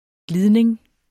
Udtale [ ˈgliðneŋ ]